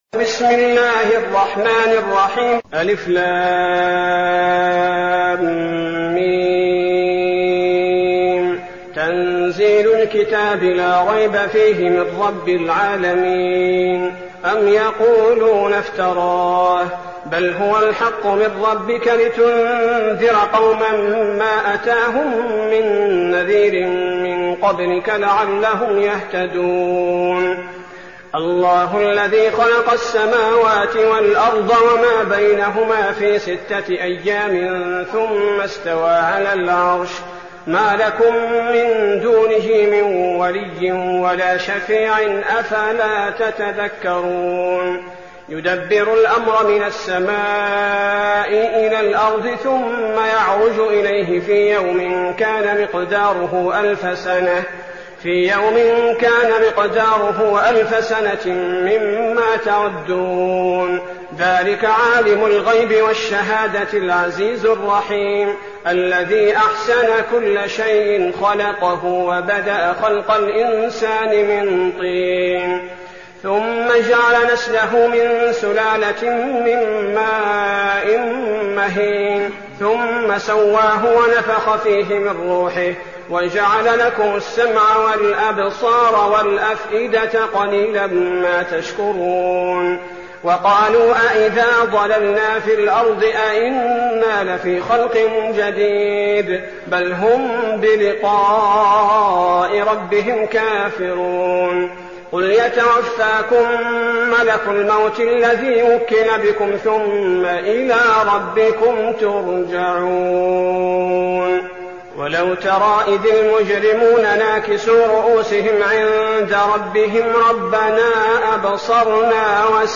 المكان: المسجد النبوي الشيخ: فضيلة الشيخ عبدالباري الثبيتي فضيلة الشيخ عبدالباري الثبيتي السجدة